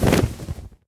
Minecraft Version Minecraft Version 25w18a Latest Release | Latest Snapshot 25w18a / assets / minecraft / sounds / mob / enderdragon / wings2.ogg Compare With Compare With Latest Release | Latest Snapshot
wings2.ogg